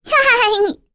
Luna-Vox_Happy1.wav